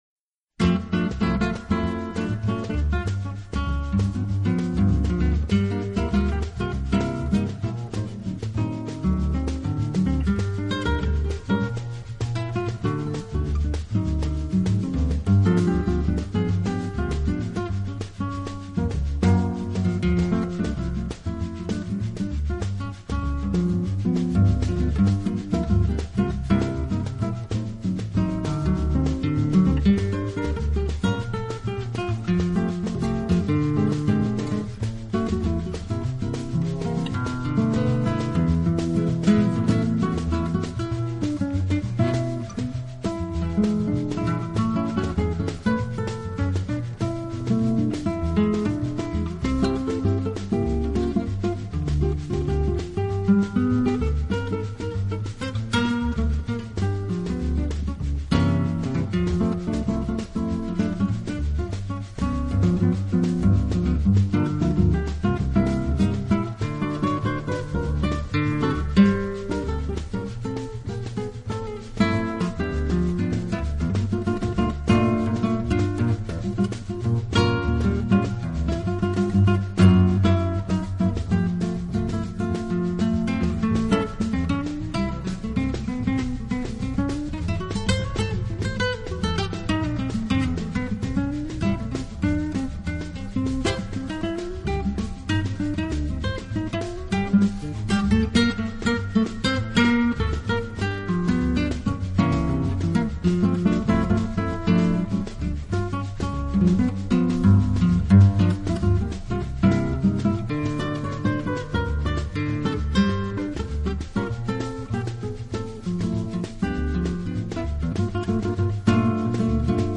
专辑类型：Jazz